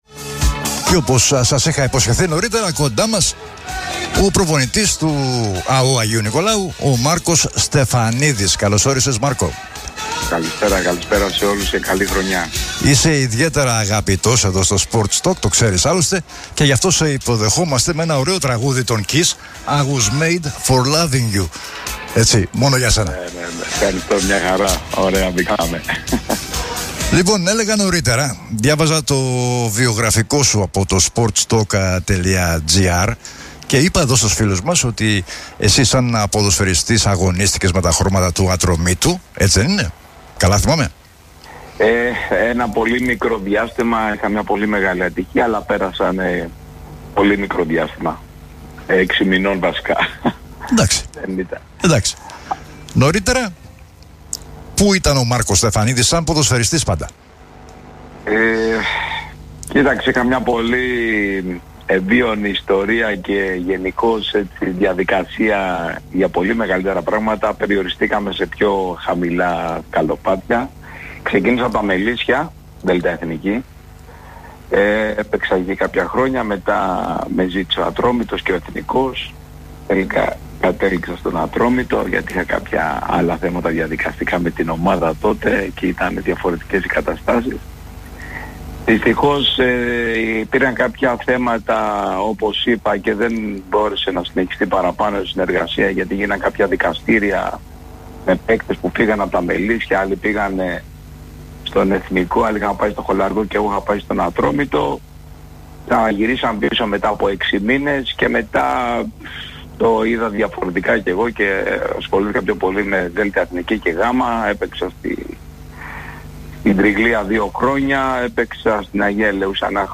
ολόκληρη η συνέντευξη στο SPORTS TALK